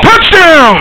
Lemmings.snd.Ship_Bump